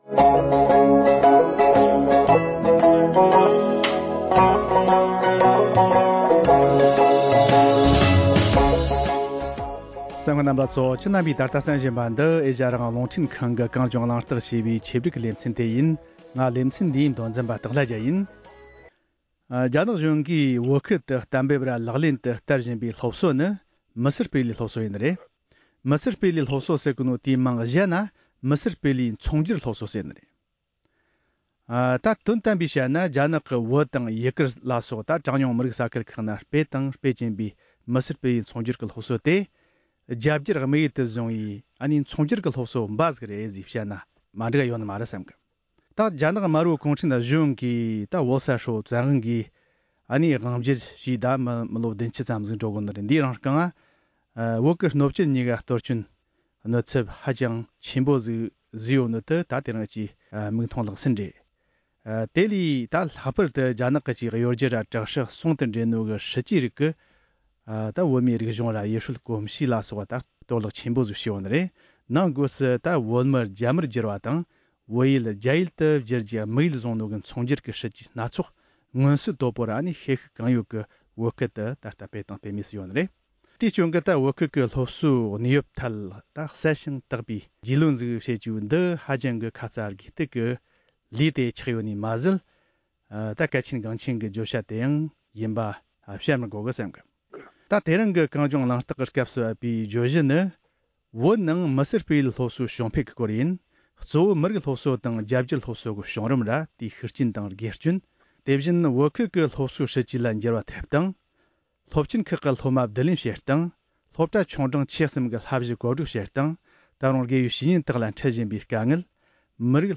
བོད་ནང་སྤེལ་བའི་སློབ་གསོའི་འབྱུང་རིམ་དང་སློབ་གསོའི་སྲིད་ཇུས་ལ་འགྱུར་བ་ཐེབས་སྟངས་དེ་བཞིན་ཤུགས་རྐྱེན་དང་དགེ་སྐྱོན་སོགས་ཀྱི་ཐད་བགྲོ་གླེང་།